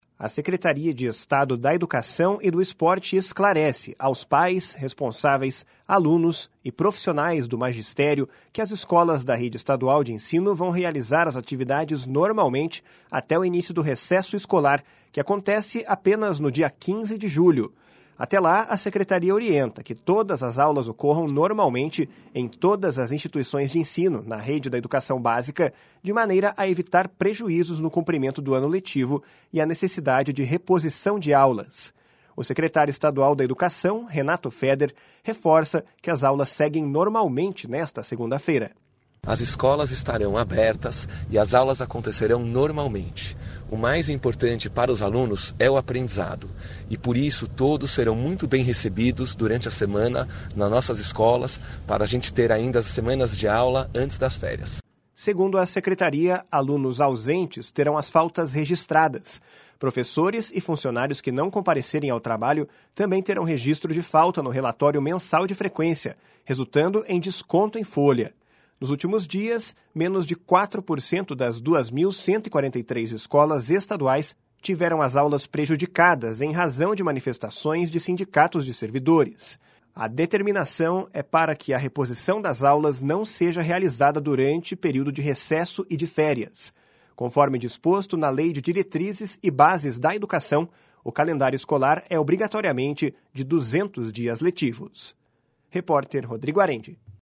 O secretário estadual da Educação, Renato Feder, reforça que as aulas seguem normalmente nesta segunda-feira. // SONORA RENATO FEDER //